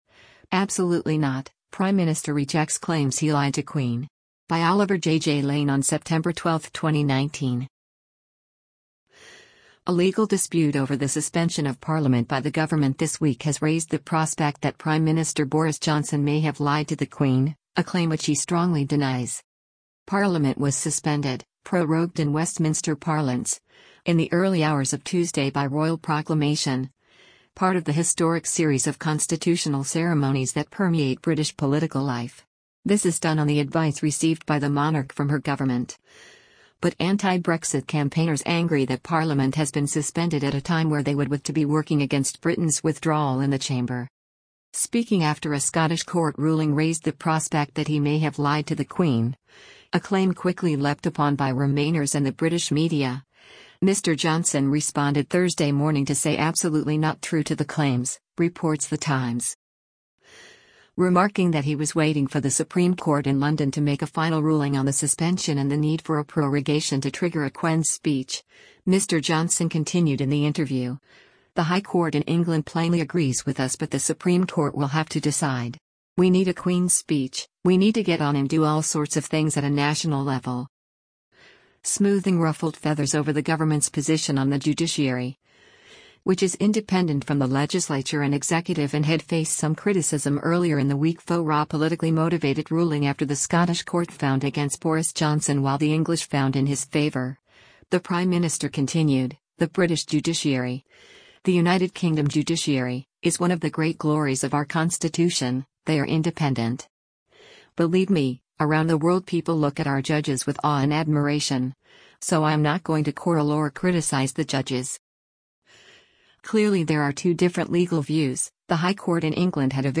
LONDON, ENGLAND - SEPTEMBER 12: U.K. Prime Minister Boris Johnson speaks to apprentices as